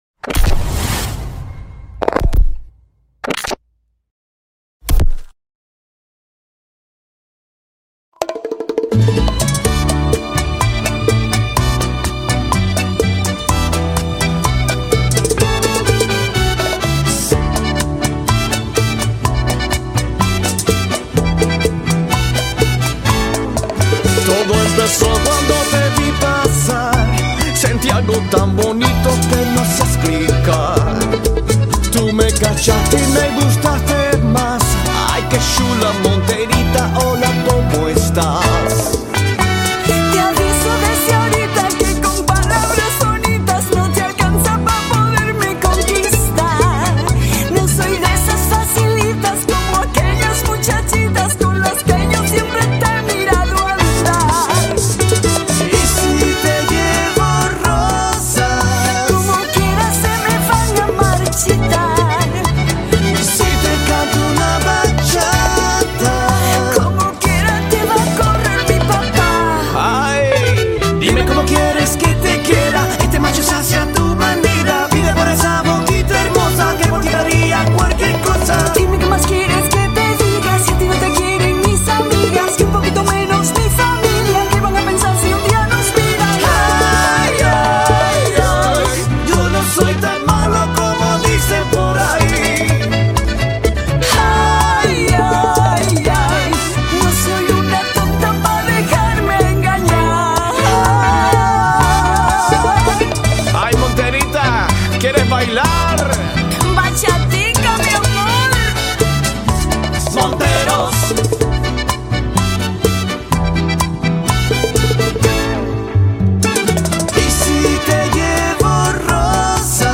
dúo
una propuesta fresca y única en versión bachata ranchera.